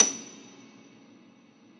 53e-pno28-F6.aif